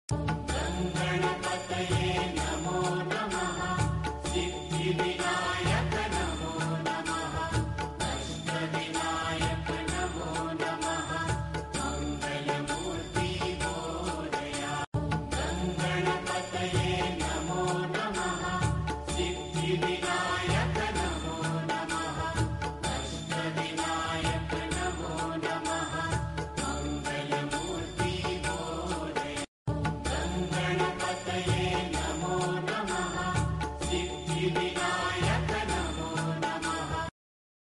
Devotional Ringtones